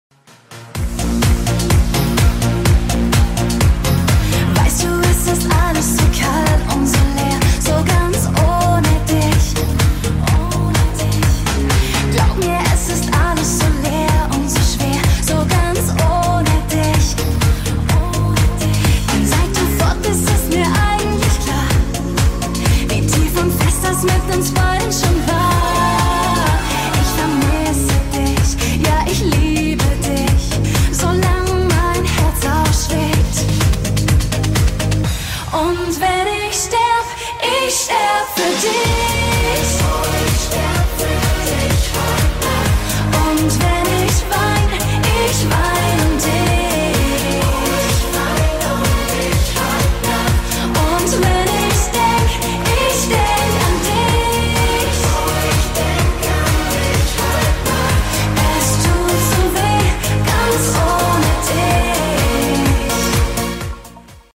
schlager